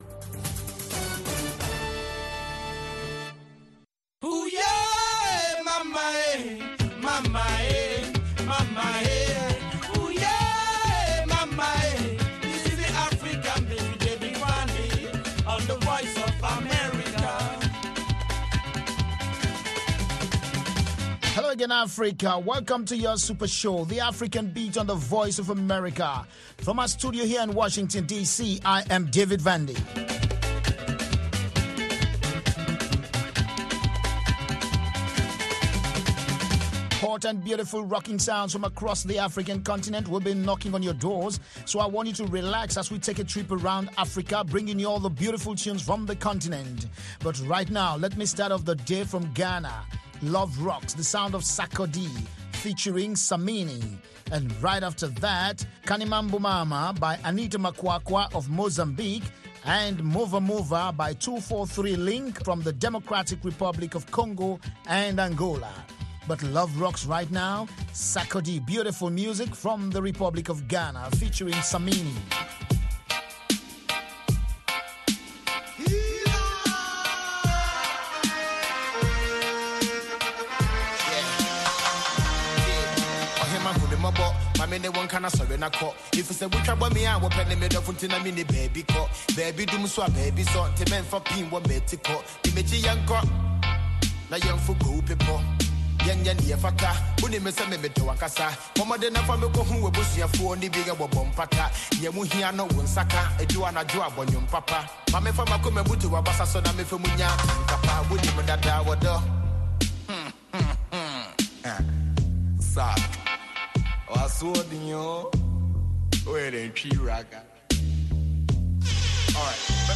African Beat showcases the latest and the greatest of contemporary African music and conversation.